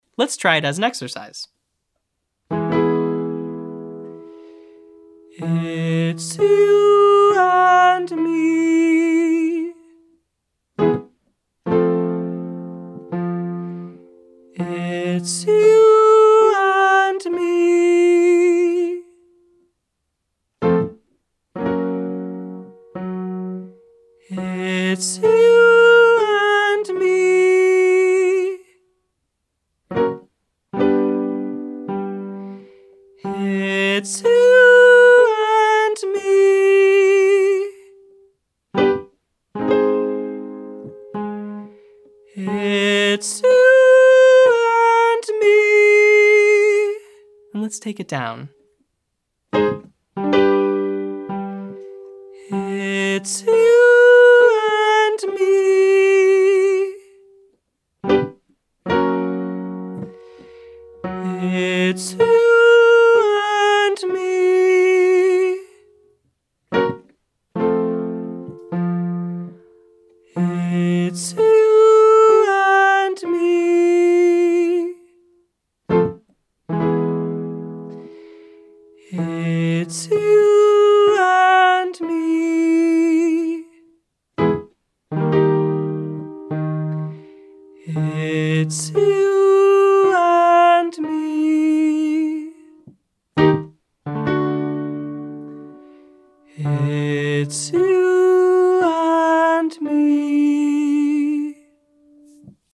Exercises 3:   Its you and me 1-10-9-8
Take a breath with the top note in mind and then allow yourself to gently tap a light breathy chest voice before jumping up into head voice.